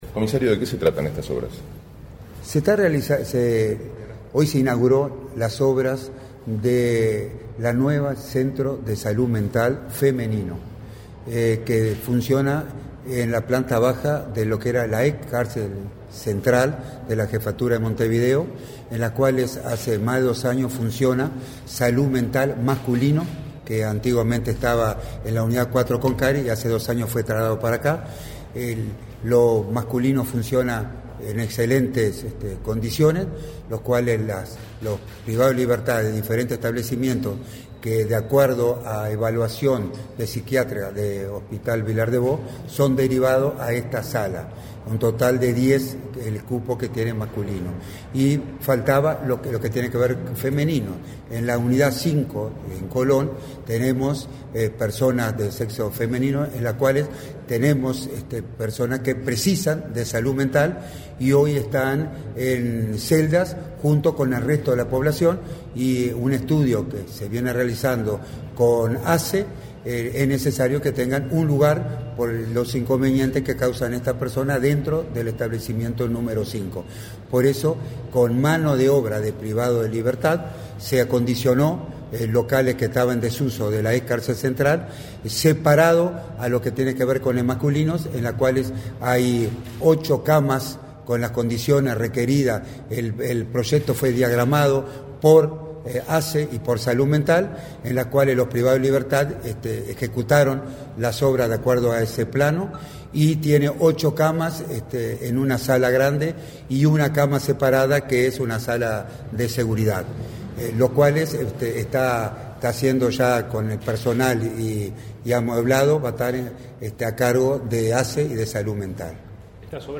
Declaraciones a la prensa del director del INR, Luis Mendoza
Declaraciones a la prensa del director del INR, Luis Mendoza 13/11/2024 Compartir Facebook X Copiar enlace WhatsApp LinkedIn La Administración de los Servicios de Salud del Estado (ASSE) inauguró el primer centro de salud mental femenino brindará atención a mujeres privadas de libertad. En la oportunidad, el director del Instituto Nacional de Rehabilitación (INR), Luis Mendoza, realizó declaraciones a la prensa.